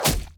Spell Impact 3.ogg